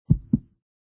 HeartBeat.mp3